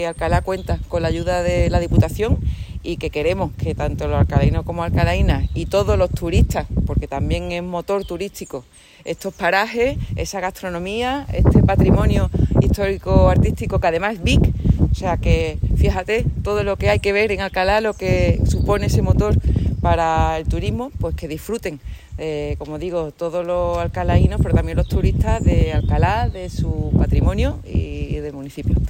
Corte de Almudena Martínez